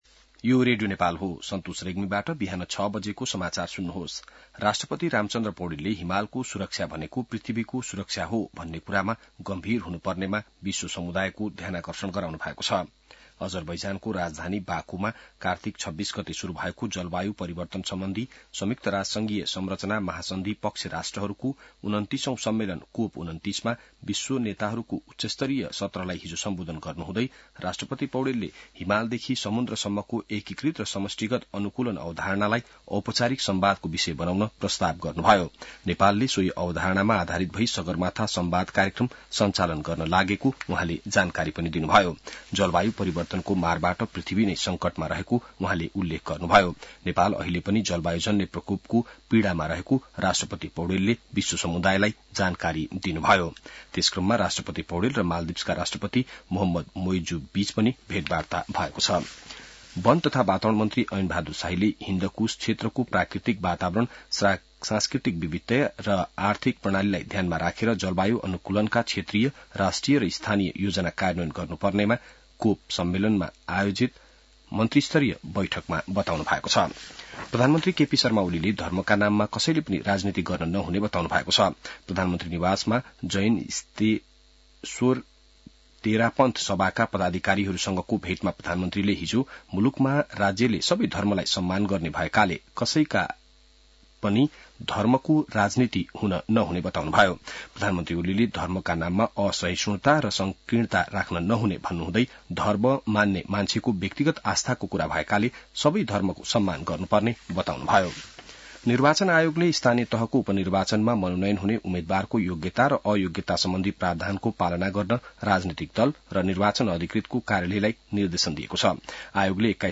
बिहान ६ बजेको नेपाली समाचार : २९ कार्तिक , २०८१